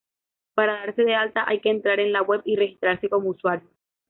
u‧sua‧rio
/uˈswaɾjo/